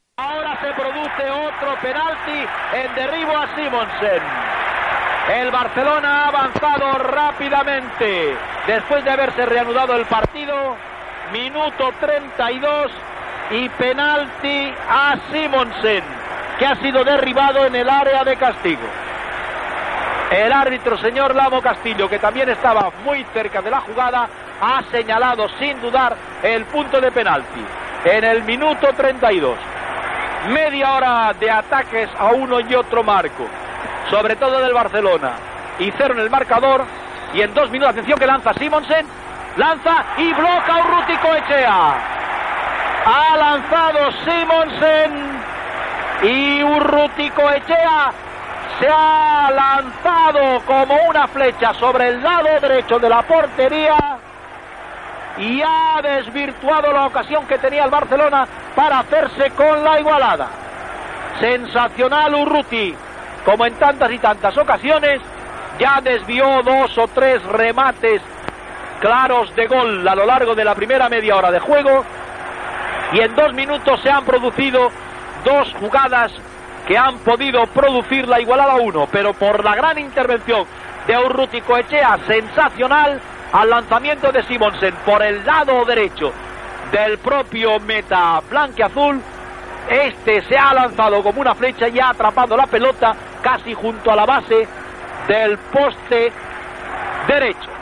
Partit de la lliga masculina de futbol entre el Futbol Club Barcelona i el Real Club Deportivo Español. Narració de la jugada que acaba en penalti a favor del Barça.
Esportiu